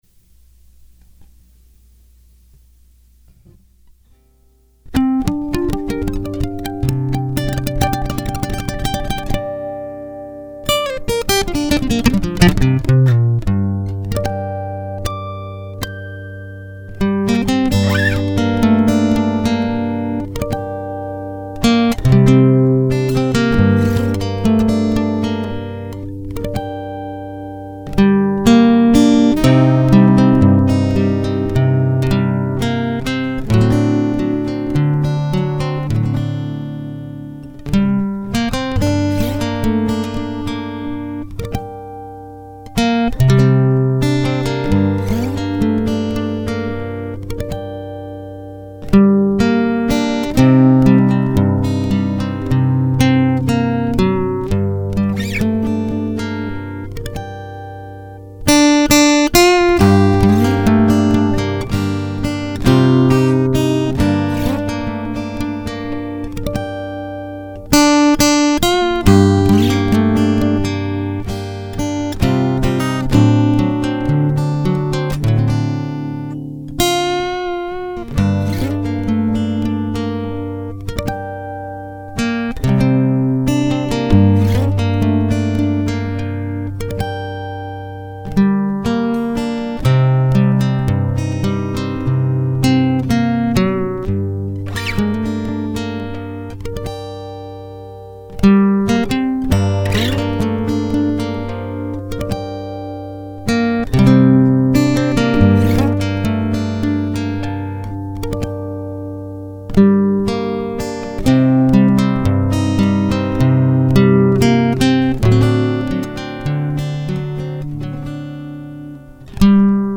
Vocals and music recorded live
Solo fingerstyle guitar.